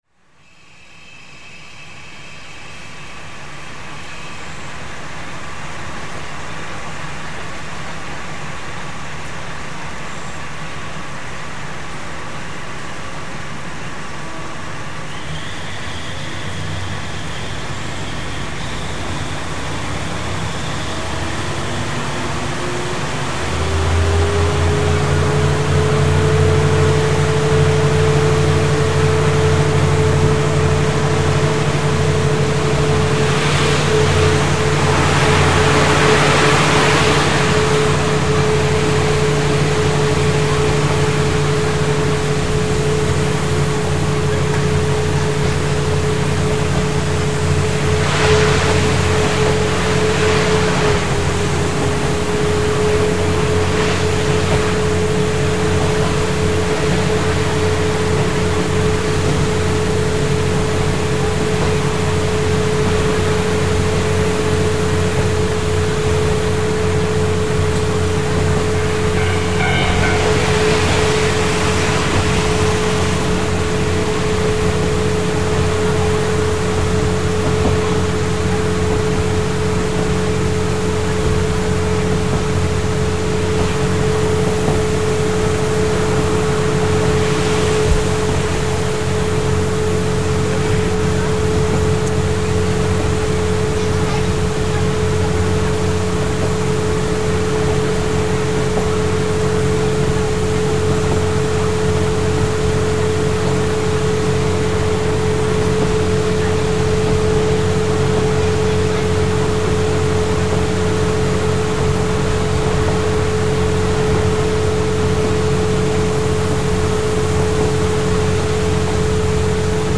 （走行音）MP3　4360KB　6分21秒
この区間も峠の頂上に向かって、ひたすら上り続けます。エンジンの轟音のわりには一向にスピードが出ません。
エンジン音は先頭のキハ５８の音と、キハ４０の音とが混じりあって、二種混合のハーモニー。？